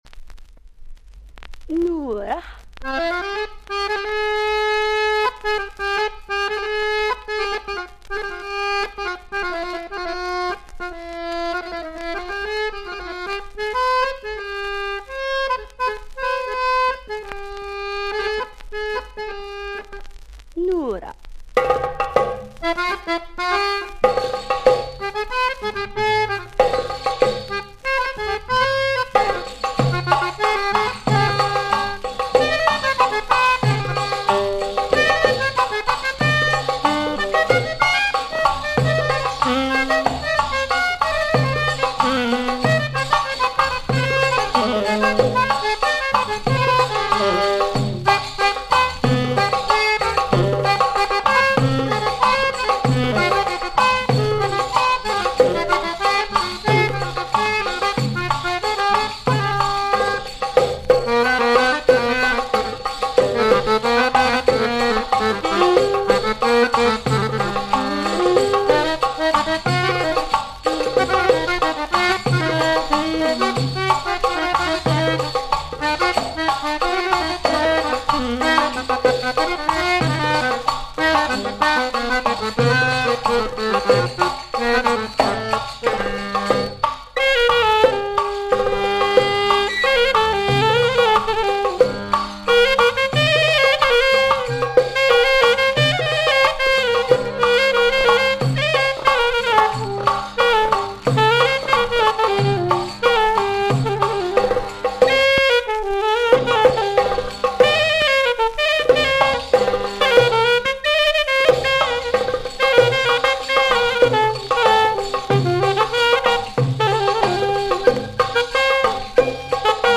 Support : 45 tours 17 cm
une voix féminine prononce deux fois le mot